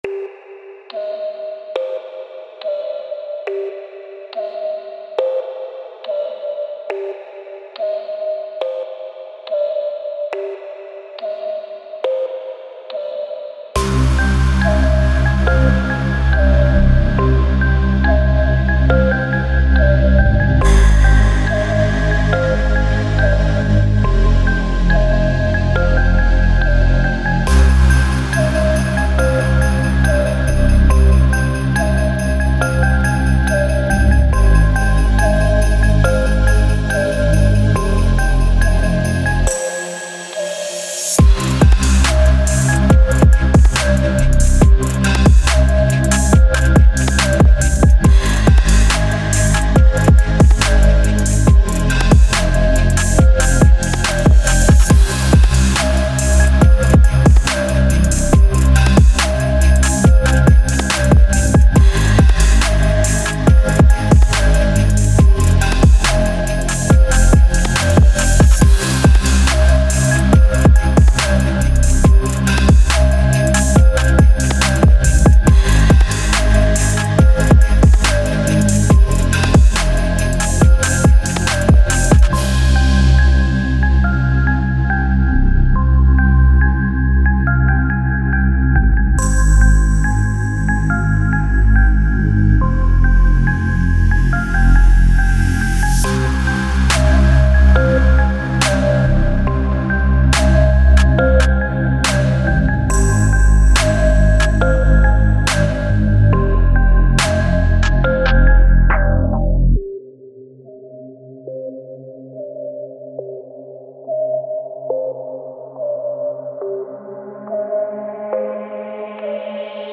Категория: Спокойная музыка
спокойные треки